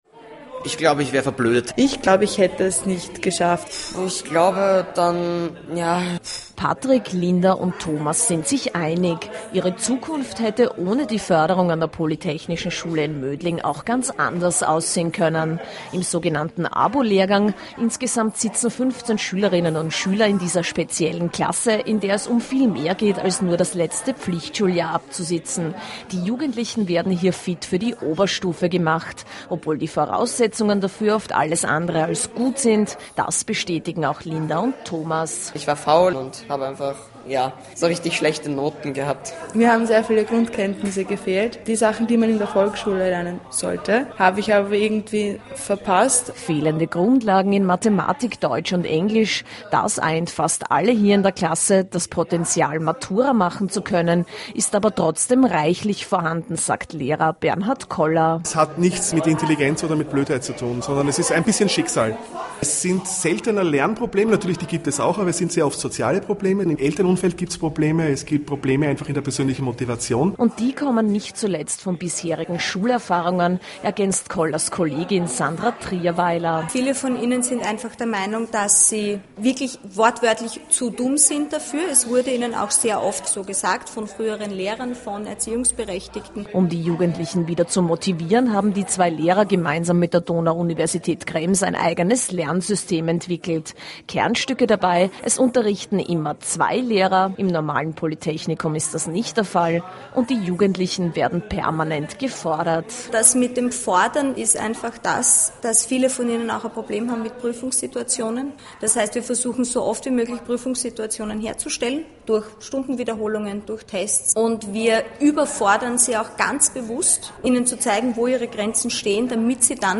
Jun. 2014, Interview Ö1 Mittagsjournal